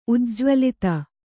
♪ ujjvalita